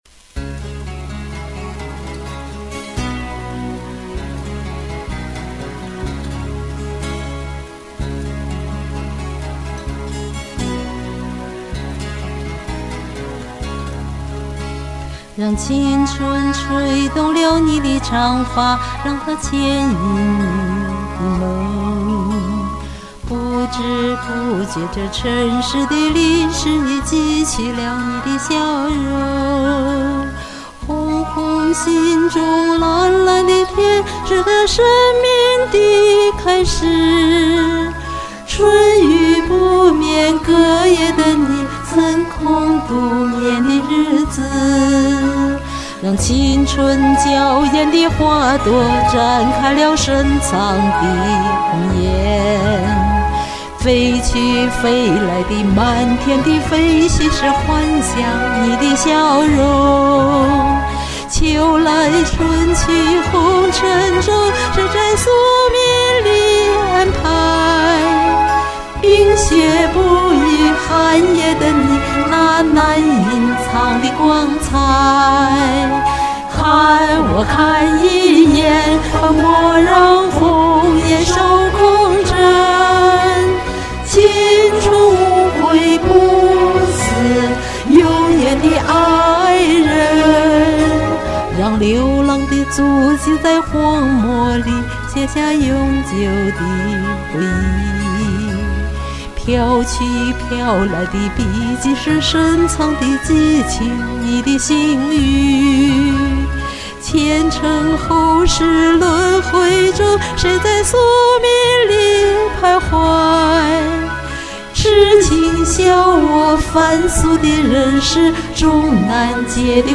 我把几版干声合成一下，最后一段整成个大合唱，因为是为你们歌唱呀！